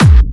VEC3 Clubby Kicks
VEC3 Bassdrums Clubby 068.wav